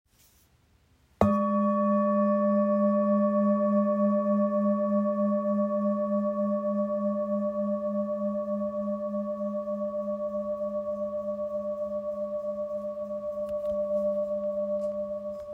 Etched Saraswati Handmade Singing Bowl – 20cm
Expertly hand-hammered by artisans, the bowl produces warm, resonant tones with long-lasting vibrations, designed to calm the mind and open space for clarity and inspiration. Its size allows for a deep, grounding sound that carries throughout a room.
The bowl measures 20cm in diameter.